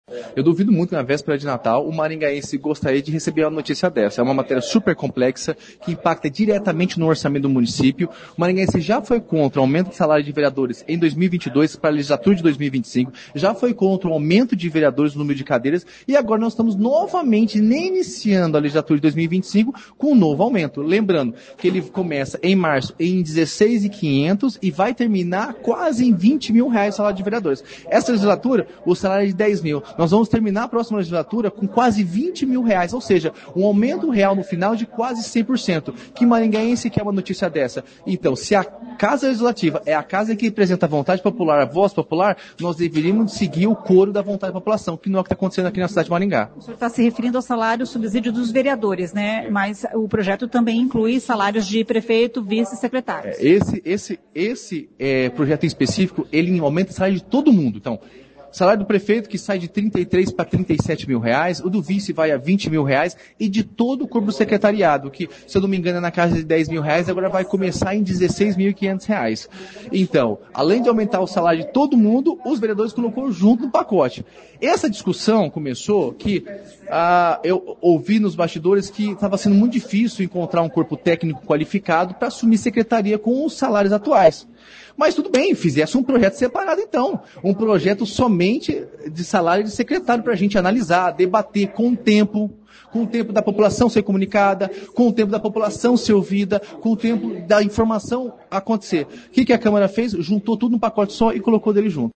O único voto contrário foi do vereador Rafael Roza. Ouça o que ele disse: